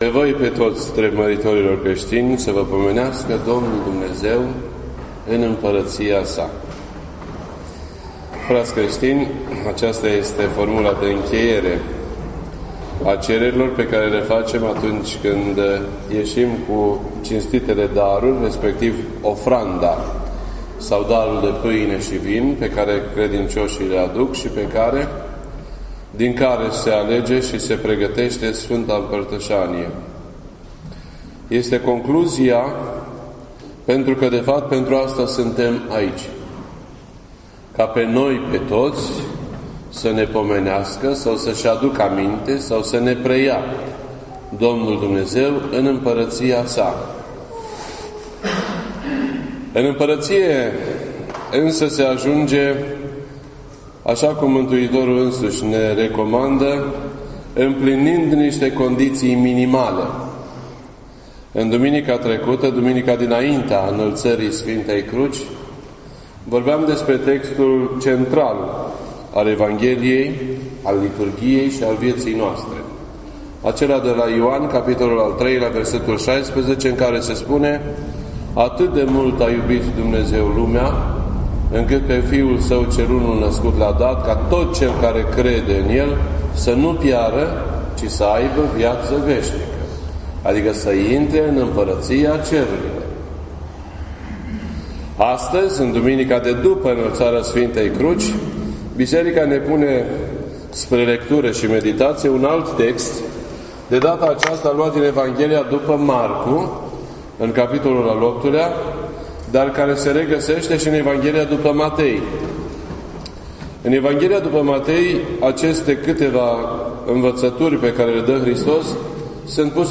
This entry was posted on Sunday, September 18th, 2016 at 10:31 AM and is filed under Predici ortodoxe in format audio.